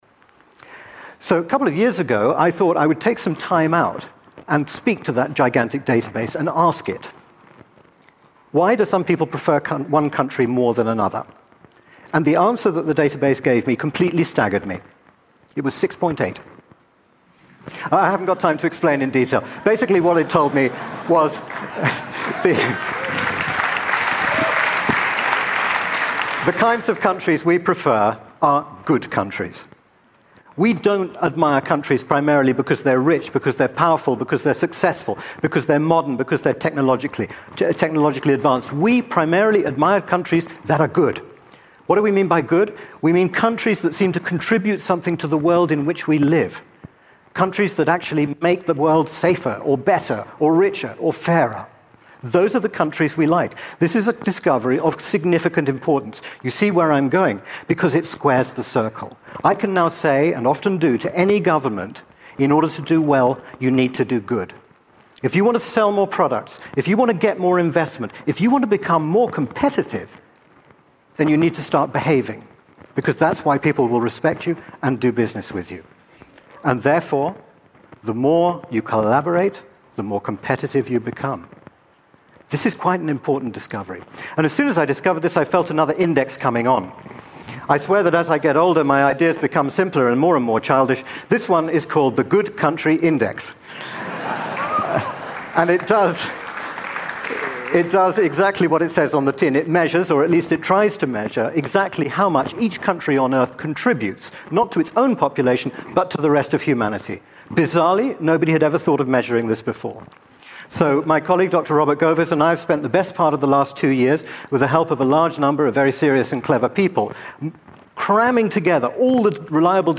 TED演讲:哪个国家对世界最有益(6) 听力文件下载—在线英语听力室